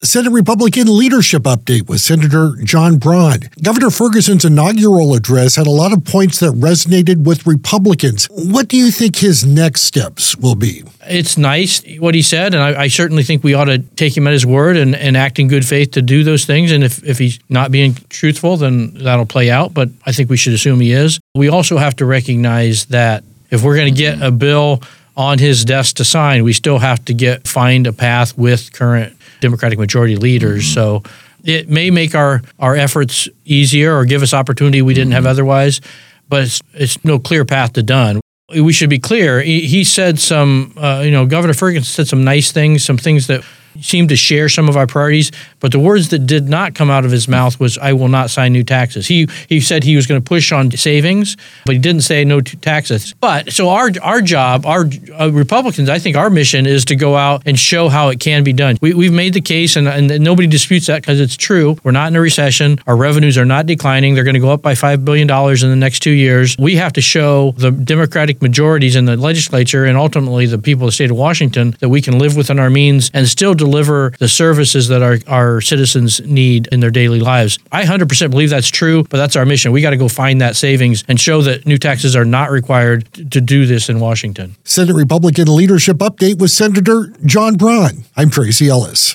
Find out what Senator John Braun thinks in this Senate Republican leadership update.